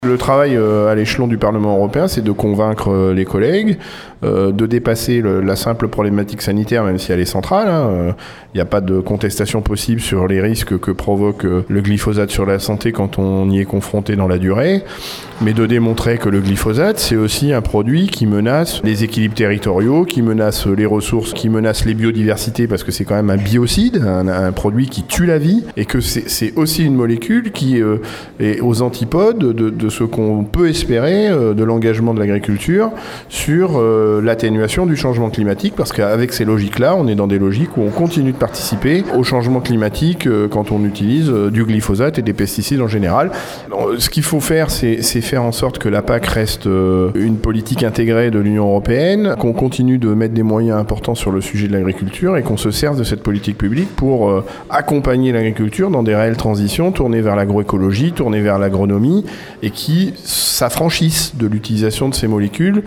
Ce matin, devant le TGI de La Rochelle.
Benoît Biteau, député européen et agriculteur bio, était présent aujourd’hui aux côtés des pisseurs volontaires. Pour lui, il faut impérativement mettre davantage de moyens pour accompagner la transition agricole :